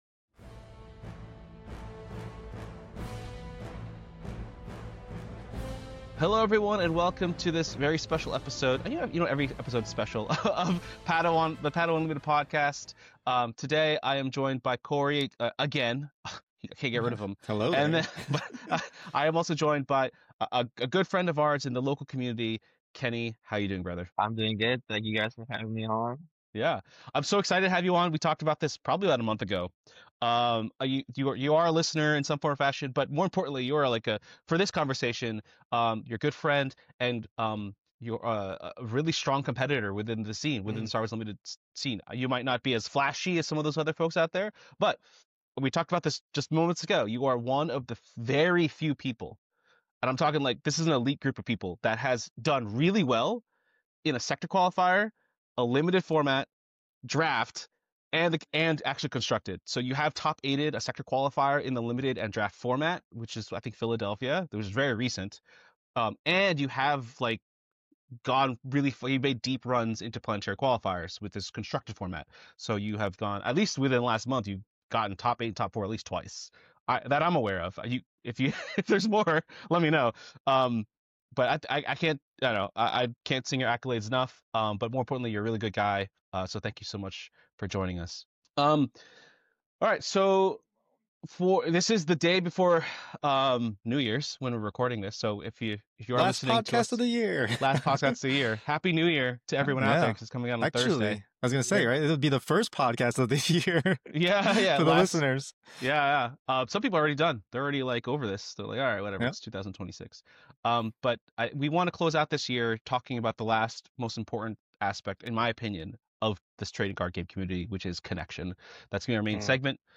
Coming to you from my brother's basement on the last day of 2025